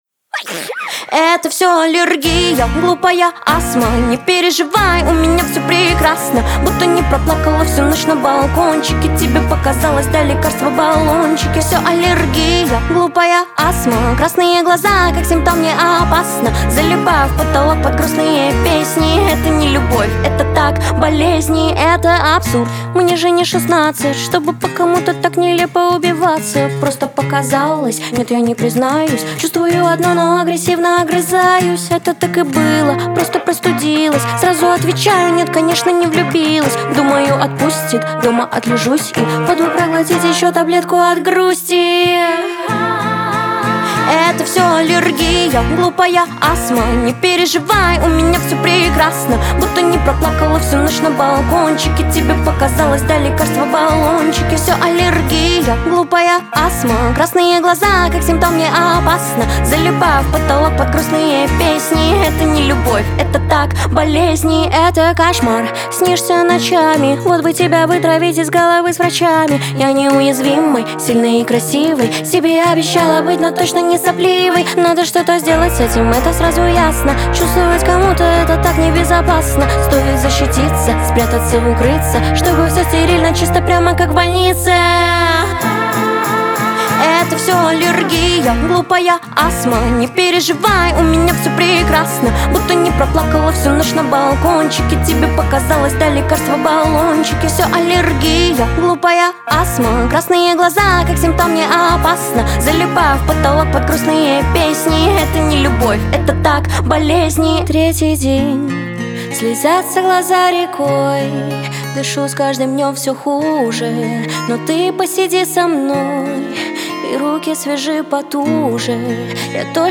Русские поп песни